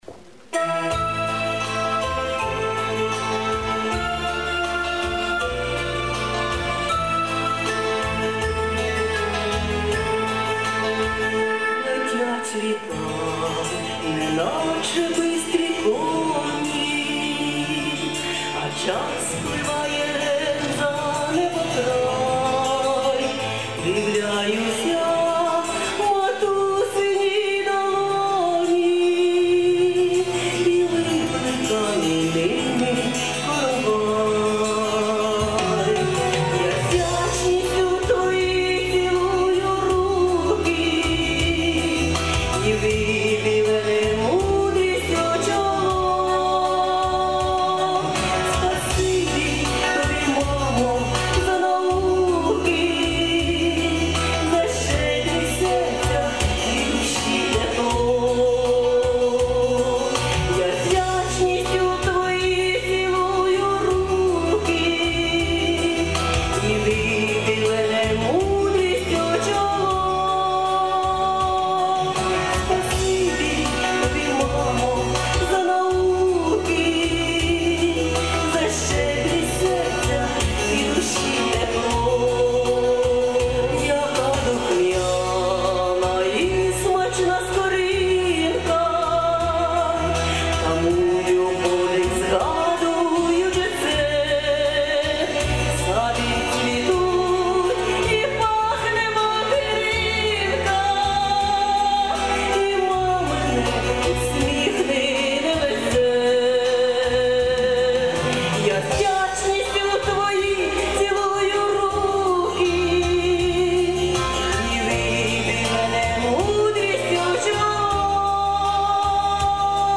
музика  Віктора Камінського,  слова  мої